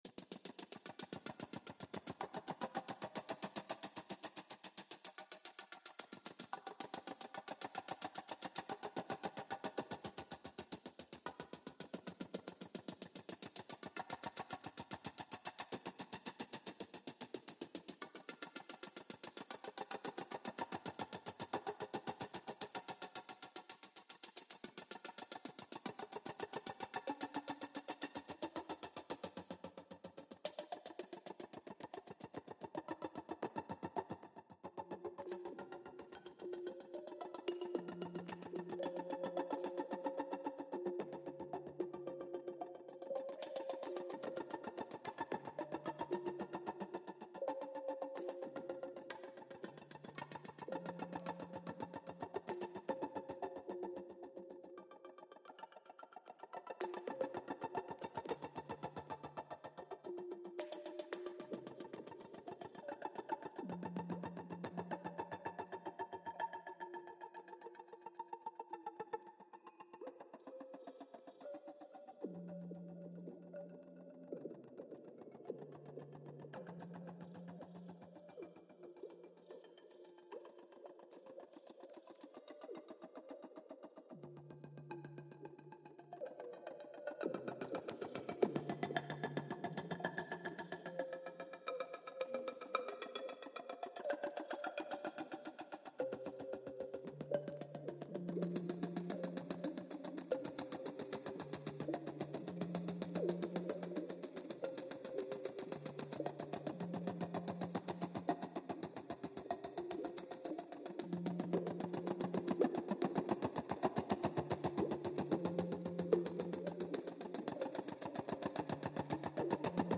Future Folk